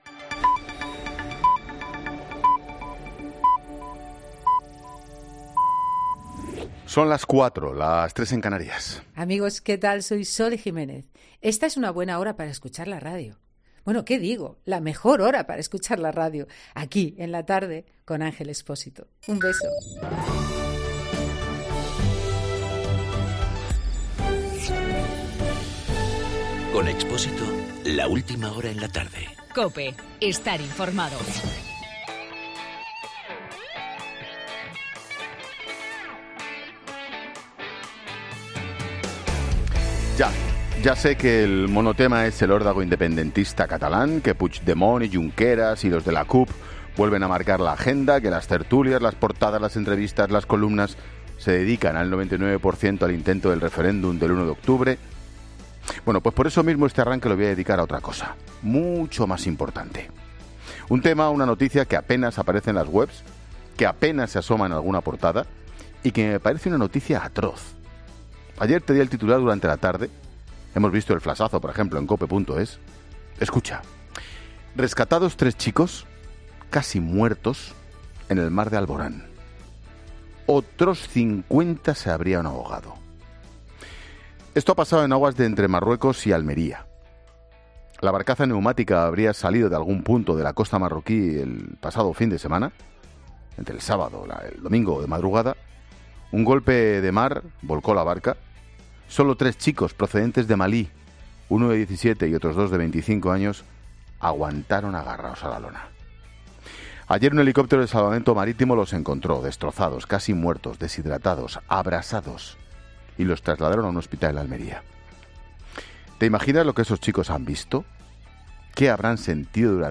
Monólogo de Expósito
Monólogo de Ángel Expósito a las 16h sobre la crisis de refugiados y sus conclusiones tras haber estado en los principales focos.